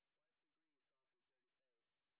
sp05_white_snr20.wav